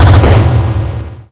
Techmino / media / effect / chiptune / clear_5.ogg
新增消5/6音效